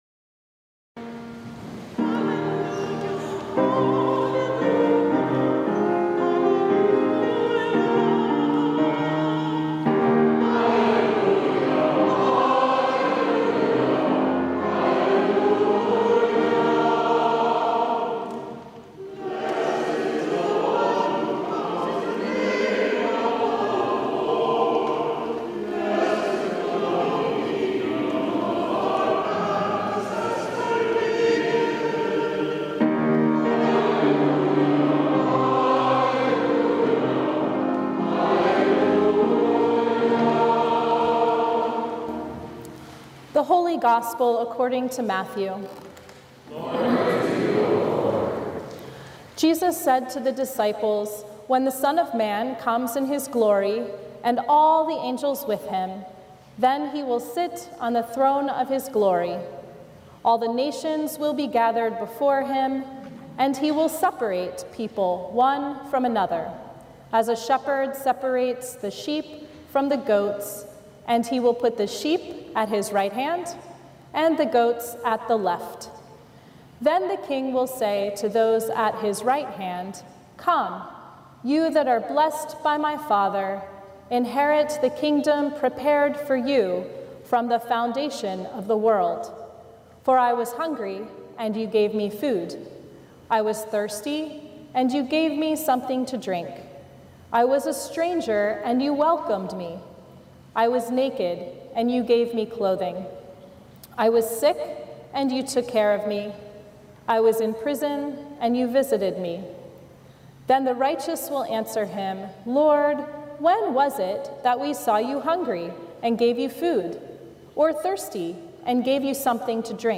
Sermons from Christ the King, Rice Village | Christ The King Lutheran Church